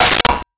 Metal Lid 06
Metal Lid 06.wav